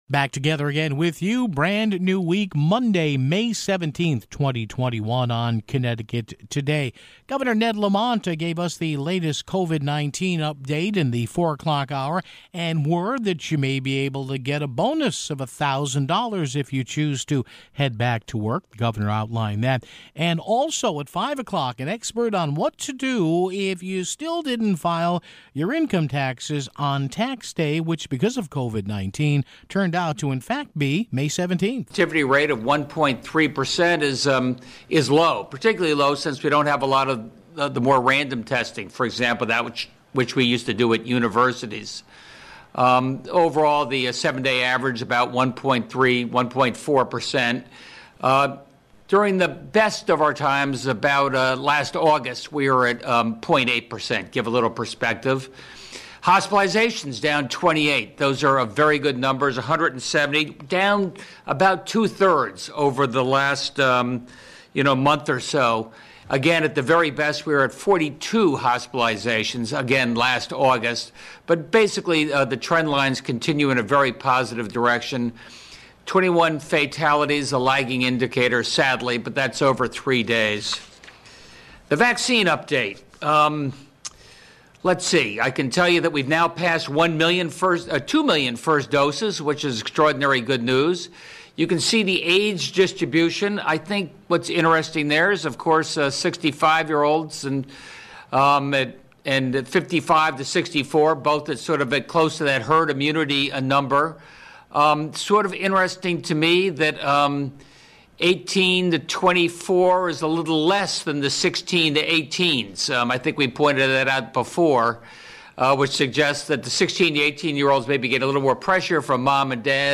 We carried Governor Ned Lamont’s news conference with a couple special guests (0:31).